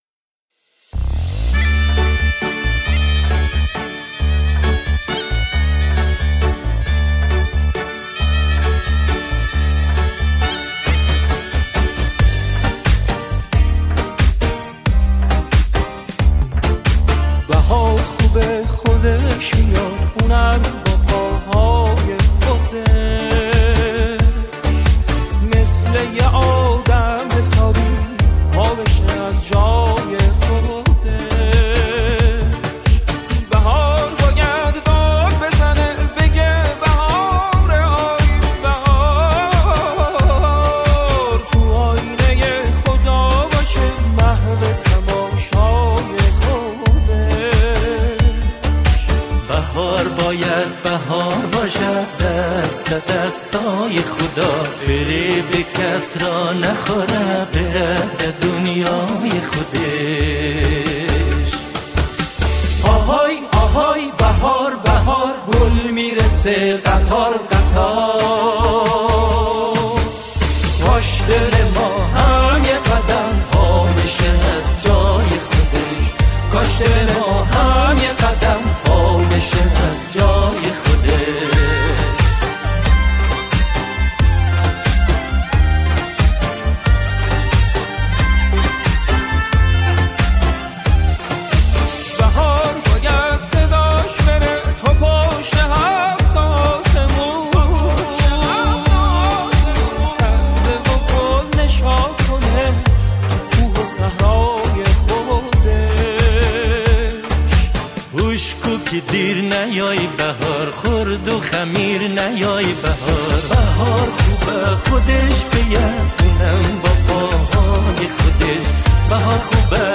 این اثر نمونه‌ای از جمعخوانی با دو خواننده اصلی است.